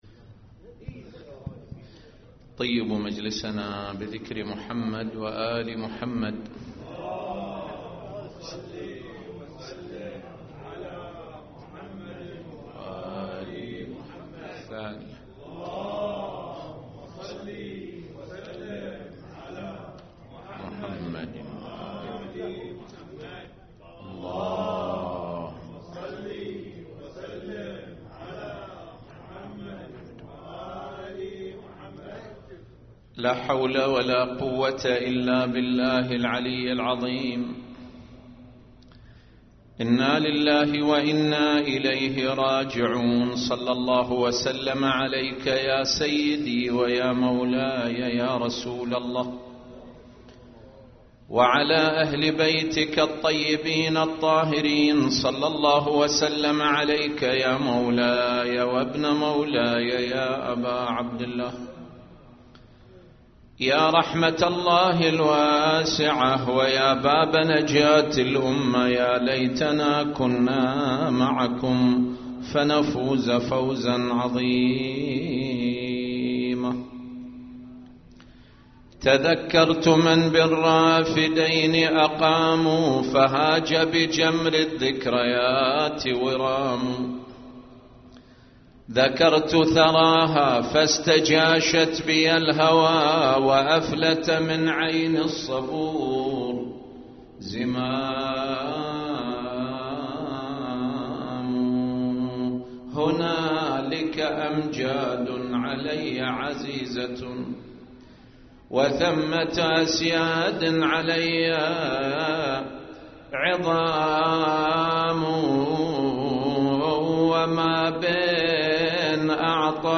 قراءة